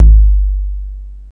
TR808BD2.wav